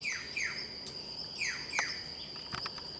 Urraca Pechinegra (Cyanocorax affinis)
Nombre en inglés: Black-chested Jay
Fase de la vida: Adulto
Localidad o área protegida: Ibague, Zona de Boquerón
Condición: Silvestre
Certeza: Vocalización Grabada